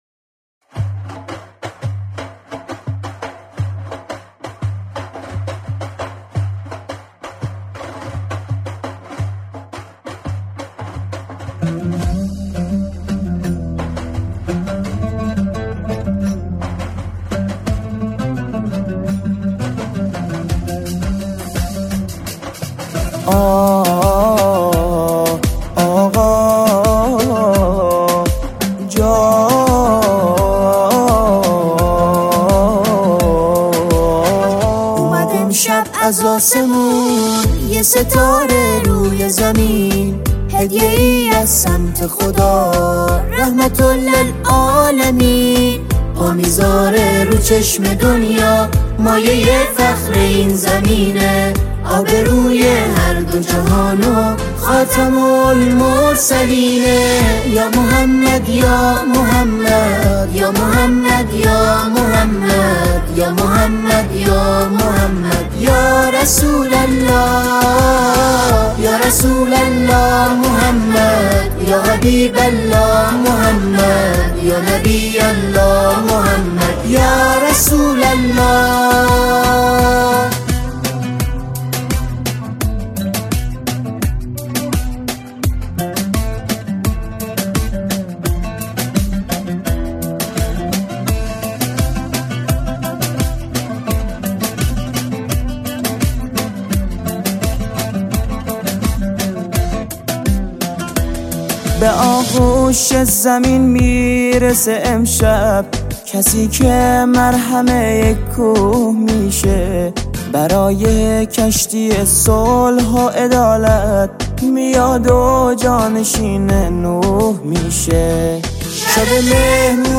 سرودهای ولادت حضرت محمد (ص)